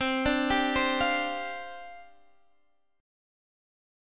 Csus2sus4 Chord